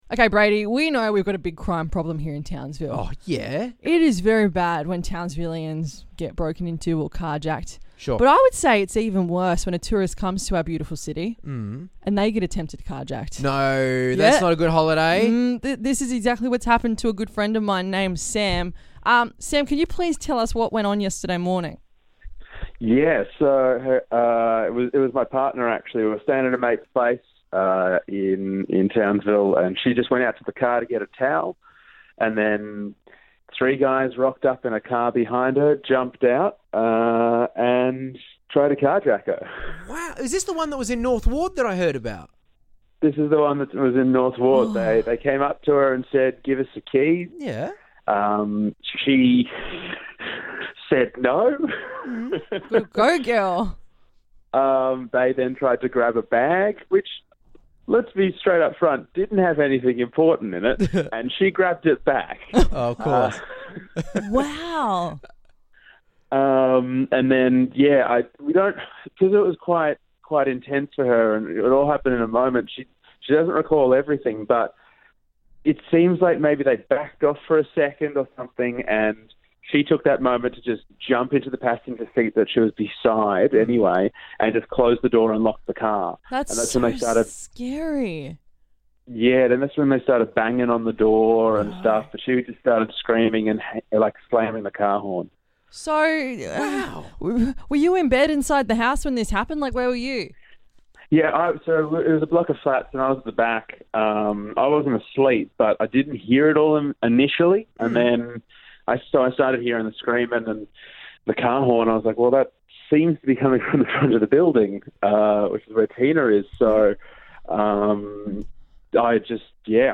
INTERVIEW: North Ward car jacking victim